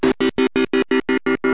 Amiga 8-bit Sampled Voice
1 channel
pulse.mp3